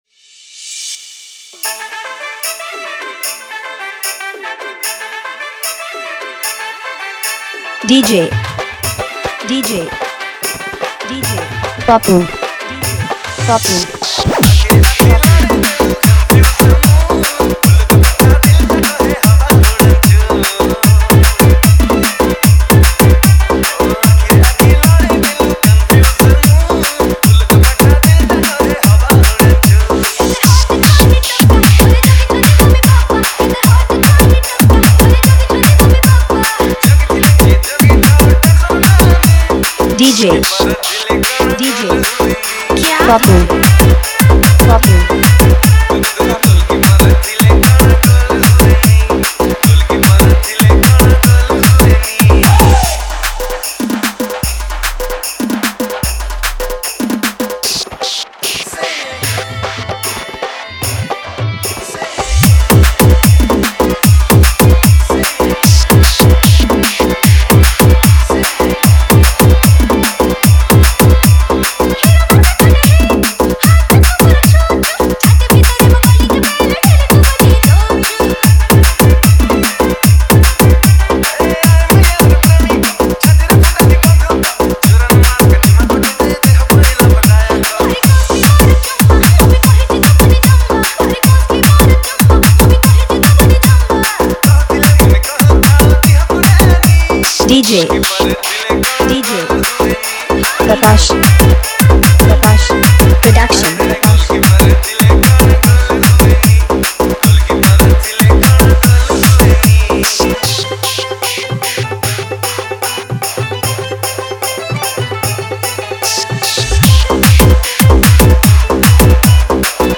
Odia Dj Song